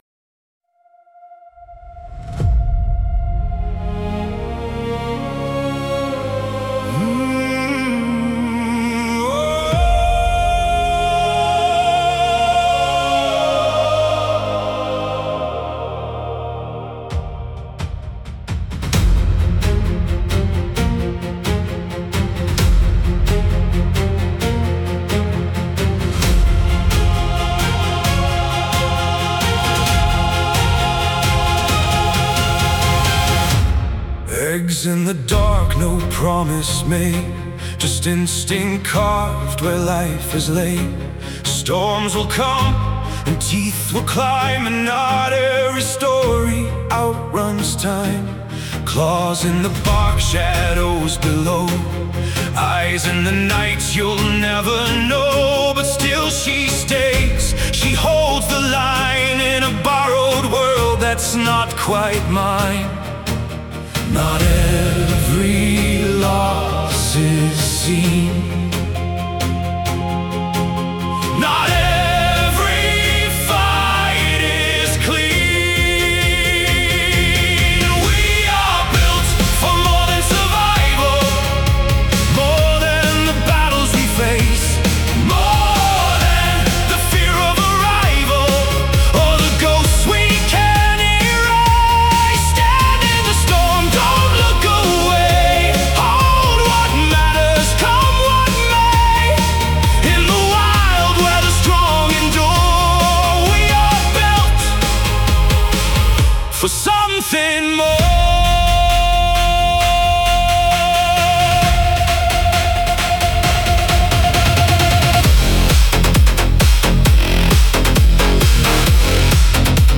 Nine songs inspired by our backyard owls and created with artificial intelligence.
A stronger anthem of hardship, renesting, resilience, and holding the line through setbacks in the owl world and beyond.